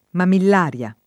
mamillaria [ mamill # r L a ] → mammillaria